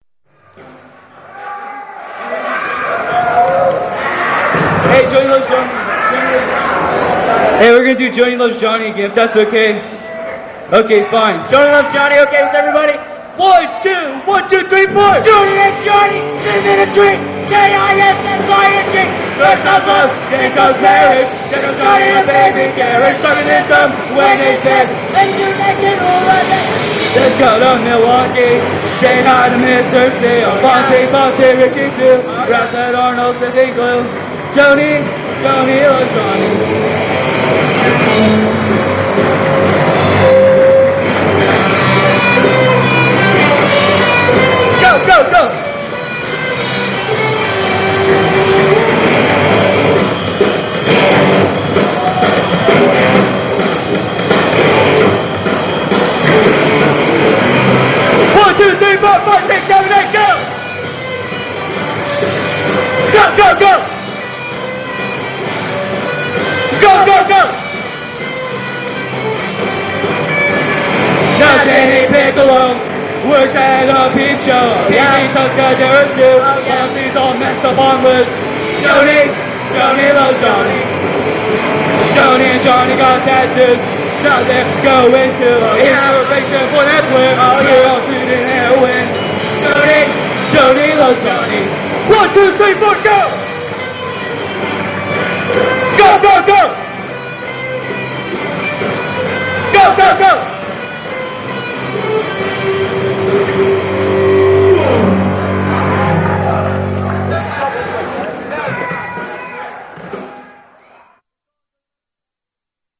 punk rock band
A blistering cover